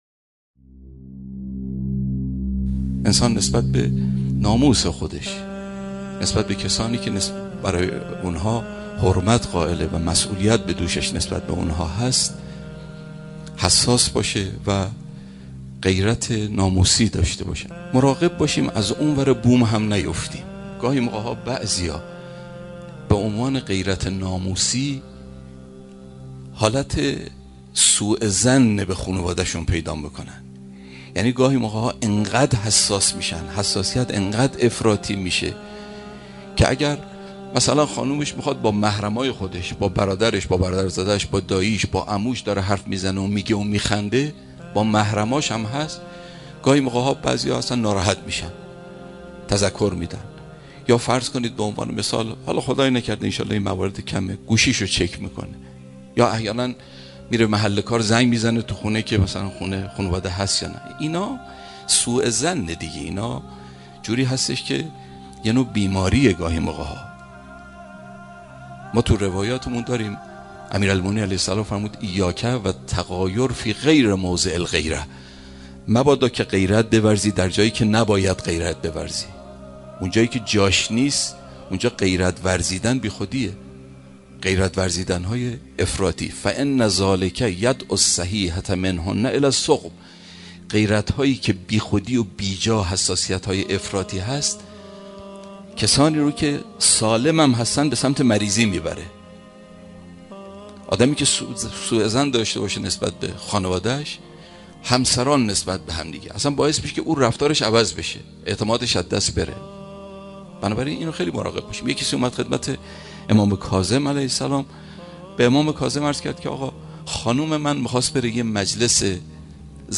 دانلود سخنرانی کوتاه و مفید حجت الاسلام عالی در مورد «غیرت نابجا»